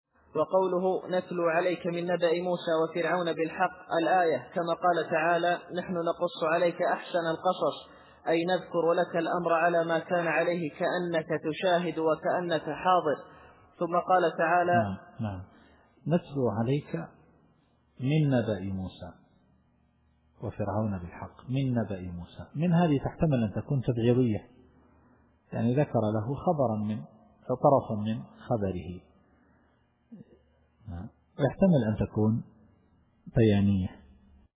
التفسير الصوتي [القصص / 3]